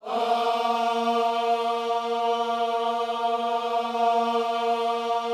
OHS A#3D  -L.wav